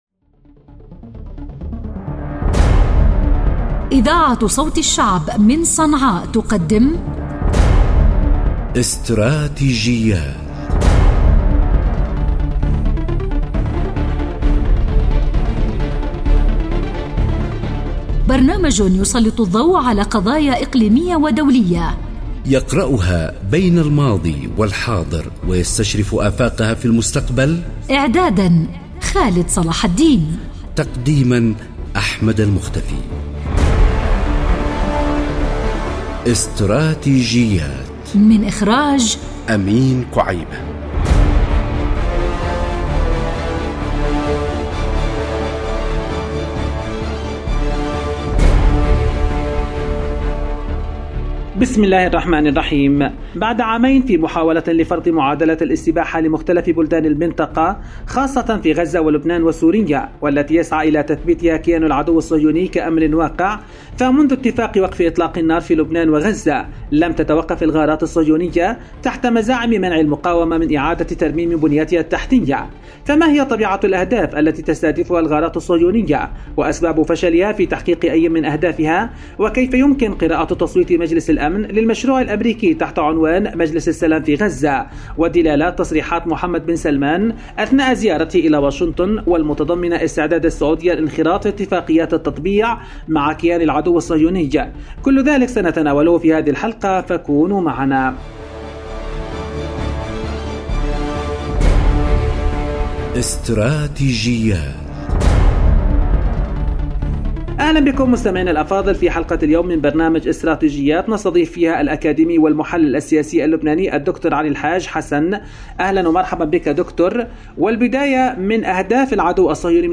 محلل سياسي لبناني